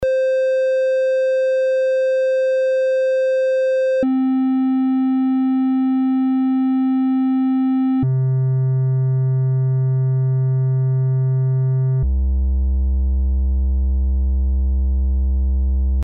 Datei) 632 KB Beispiel Dreiecksound 1